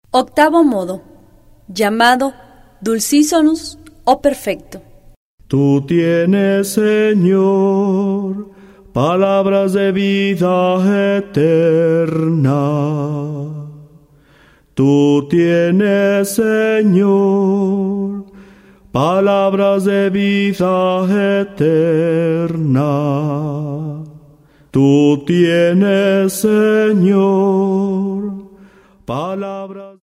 09 Octavo modo gregoriano.